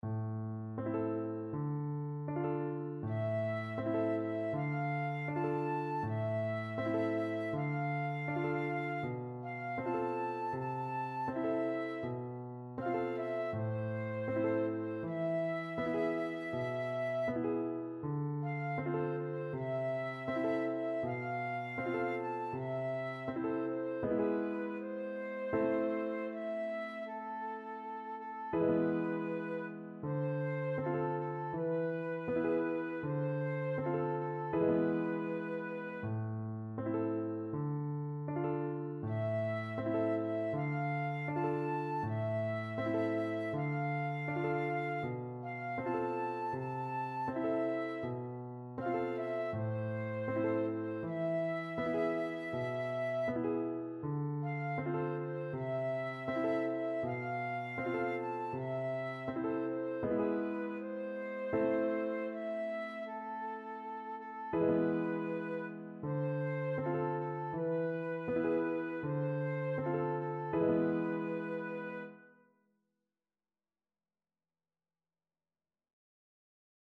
Traditional Trad. Chugoku Chiho no Komori-uta (Chugoku Region Lullaby) Flute version
Flute
A minor (Sounding Pitch) (View more A minor Music for Flute )
4/4 (View more 4/4 Music)
Andante
A5-A6
Traditional (View more Traditional Flute Music)
chugoku_FL.mp3